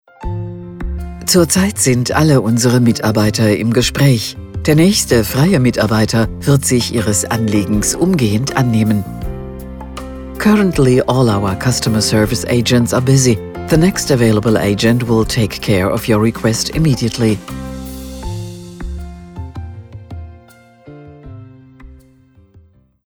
Telefonansagen mit echten Stimmen – keine KI !!!
Alle Mitarbeiter im Gespräch – Braum Hamburg – exklusive Herrenmode